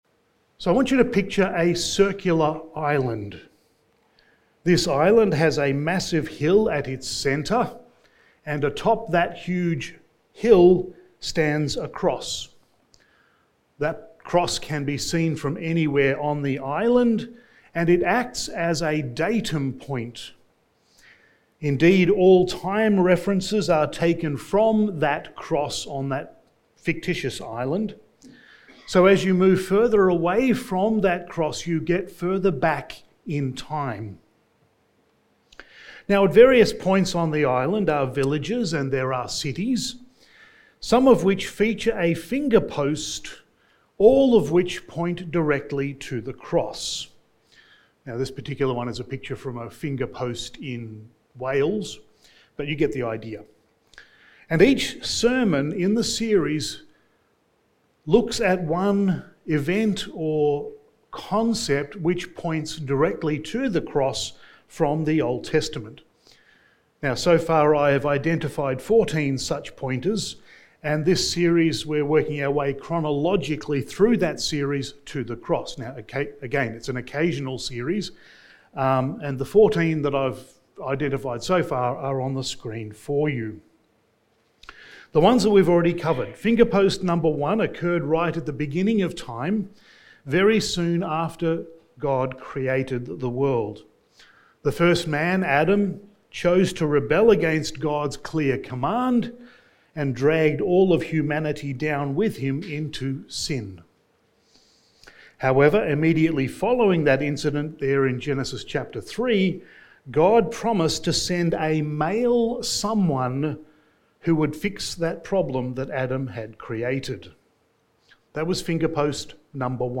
Sermon 35 in the Genesis Series and Sermon 3 in the Old Testament Pointers to the Cross